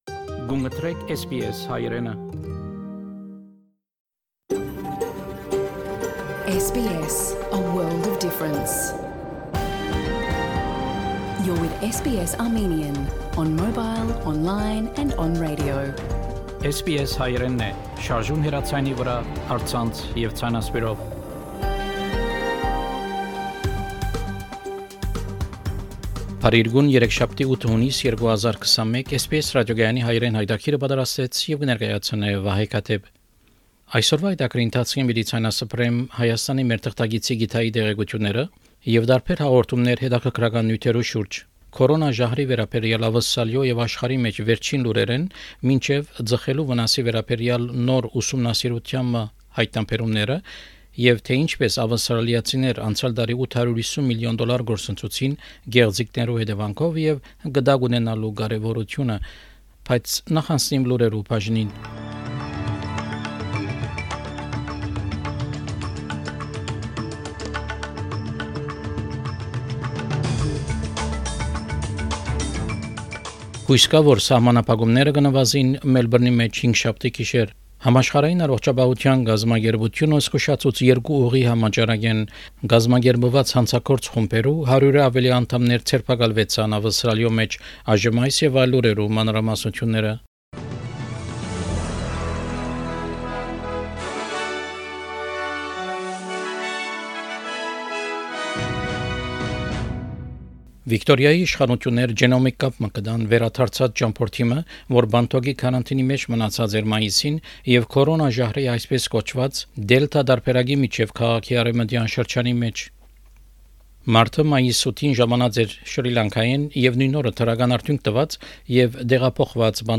SBS Armenian news bulletin – 8 June 2021
SBS Armenian news bulletin from 8 June 2021 program.